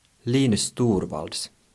Linus Benedict Torvalds (/ˈlnəs ˈtɔːrvɔːldz/ LEE-nəs TOR-vawldz,[3] Finland Swedish: [ˈliːnʉs ˈtuːrvɑlds]